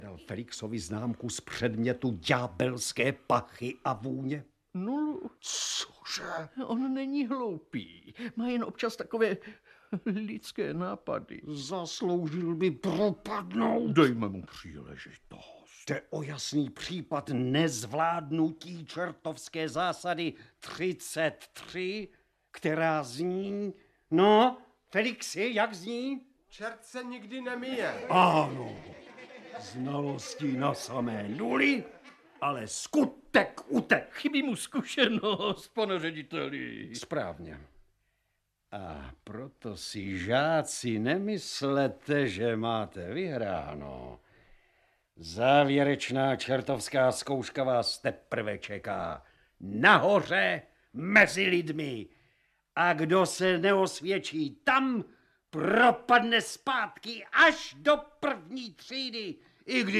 Hörbuch
MP3 Audiobook,